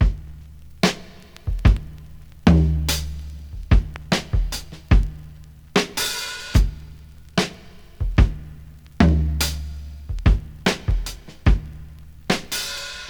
• 73 Bpm Breakbeat G Key.wav
Free drum beat - kick tuned to the G note. Loudest frequency: 1252Hz
73-bpm-breakbeat-g-key-aMP.wav